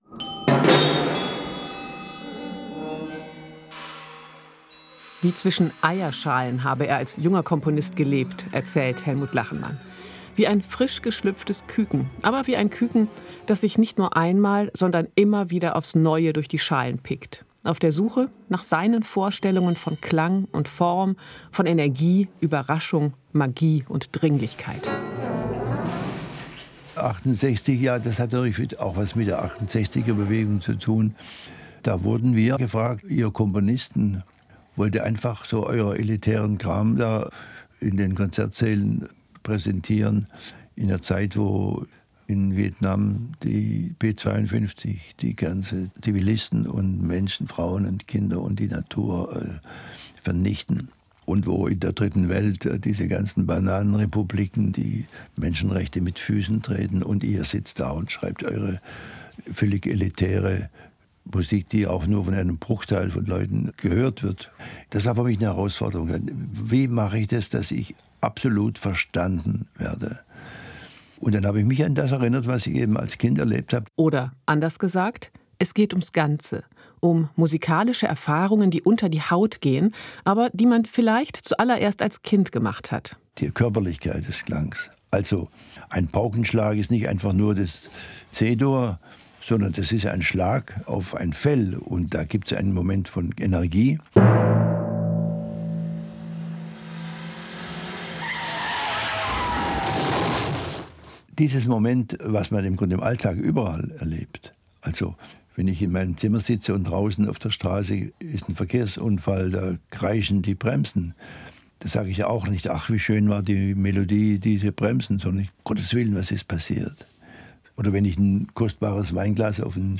Portrait
Ein Orchester, das „nur“ Geräusche produziert. Streicher, deren Bögen schaben und knarzen, auch flüstern. Bläser, die den Ton mit Luft und Lebensgeräuschen mischen.